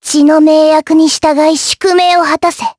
Lewsia_A-Vox_Skill5_jp_b.wav